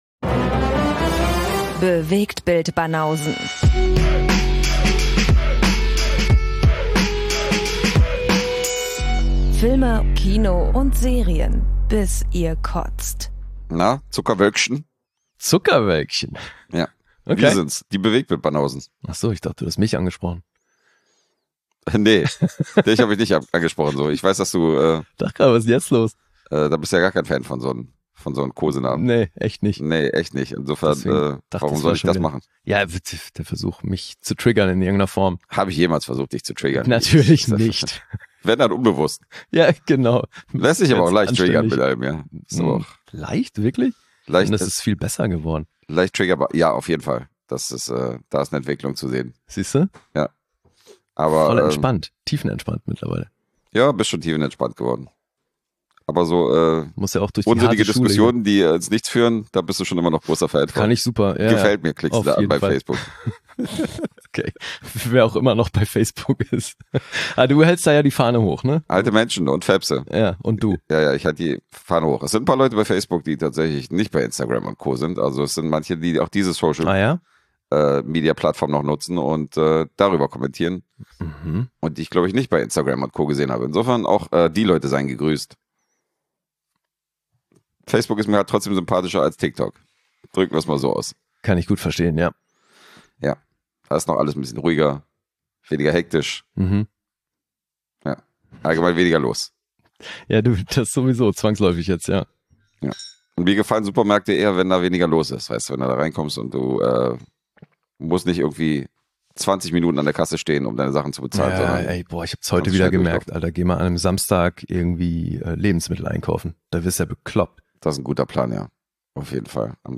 Zwei Dudes - manchmal mit Gästen - quatschen über Gesehenes aus Kino, Homekino und Streaming-Plattformen und punkten zudem mit gefährlichem Halbwissen.